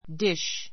díʃ ディ シュ